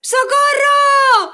Mujer pidiendo ayuda: ¡Socorro! 2
exclamación
mujer
Sonidos: Voz humana